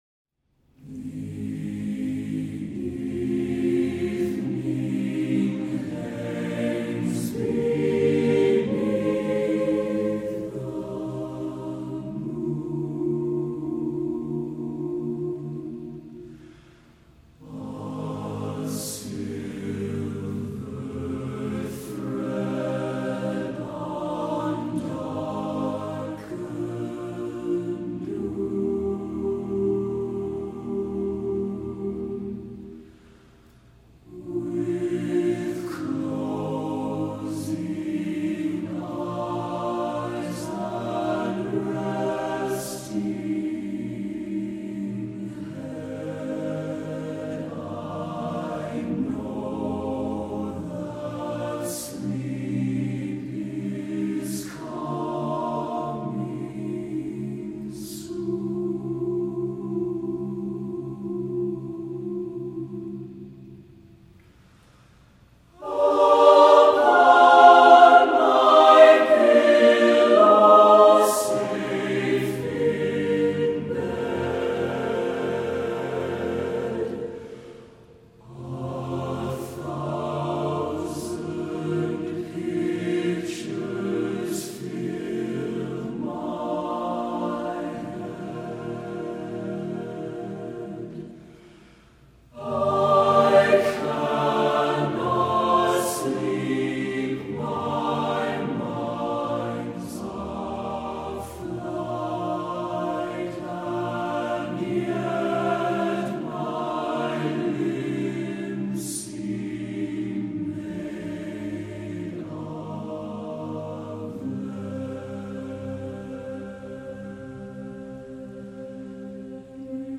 Accompaniment:      A Cappella
Music Category:      Choral